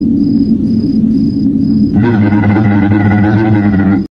Goofy Ahh Noises Meme Very Weird Sound Button - Free Download & Play